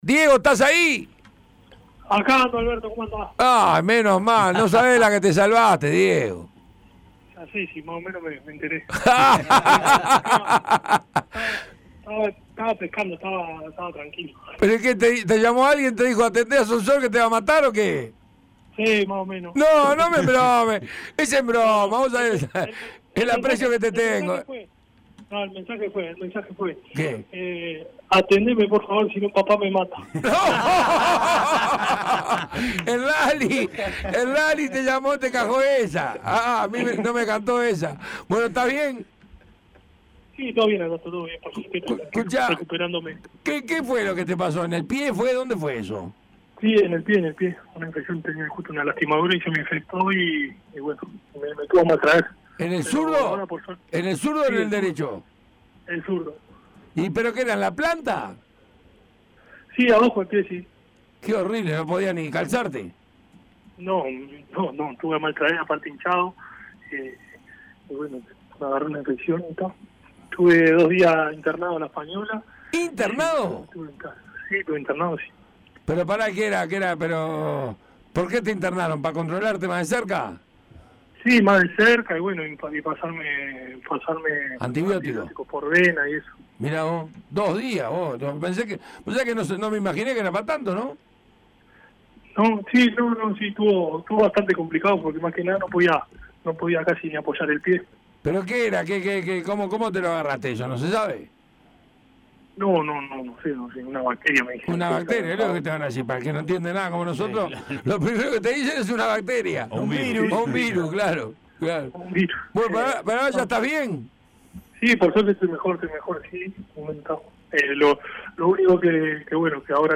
El capitán de Nacional, Diego Polenta, habló con el panel de Tuya y Mía respecto a su actualidad personal, el presente del club y se refirió también a otros temas que están sobre la mesa: la Copa Libertadores, la selección Sub 20 en el Sudamericano y la violencia en nuestro fútbol.